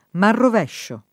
[ manrov $ ššo ]